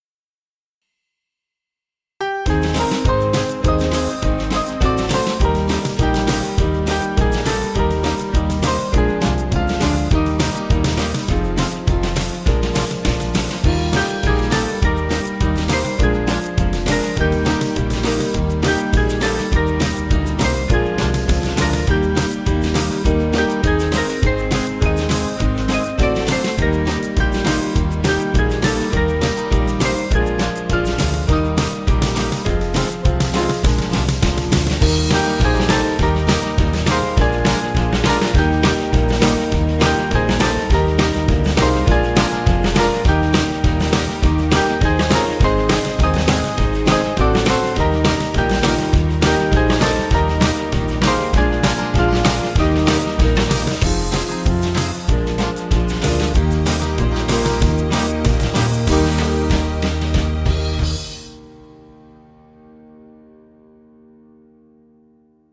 Small Band
(CM)   2/Eb 384.3kb